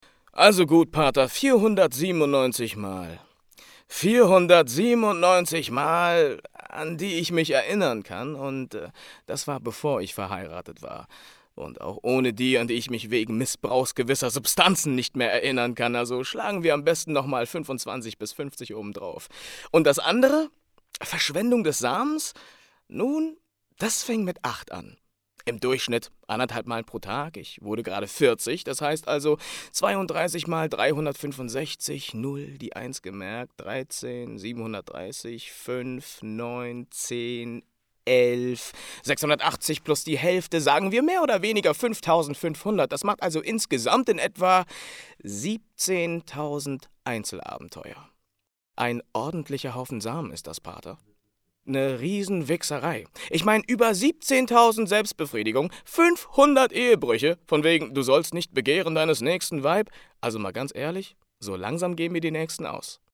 Theaterschauspieler und Sänger
Sprechprobe: Industrie (Muttersprache):